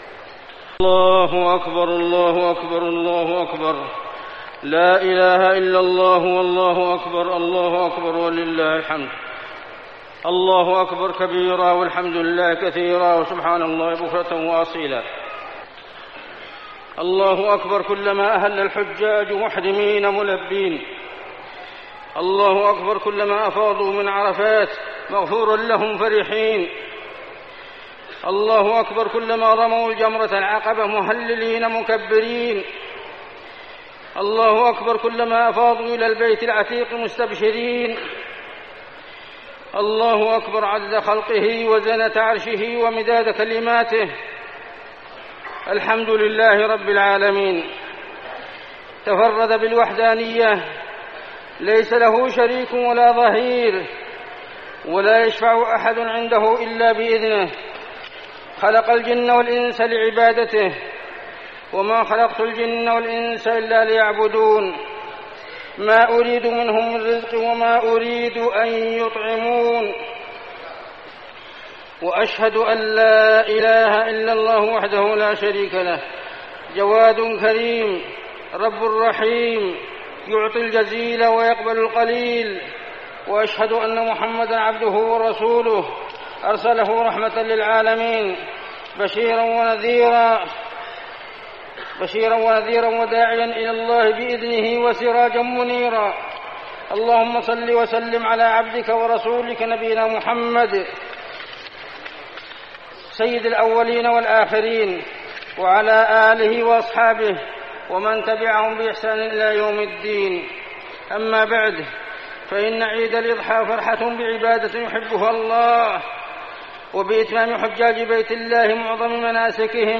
خطبة عيد الأضحى - المدينة - الشيخ عبدالله الزاحم
تاريخ النشر ١٠ ذو الحجة ١٤١٦ هـ المكان: المسجد النبوي الشيخ: عبدالله بن محمد الزاحم عبدالله بن محمد الزاحم خطبة عيد الأضحى - المدينة - الشيخ عبدالله الزاحم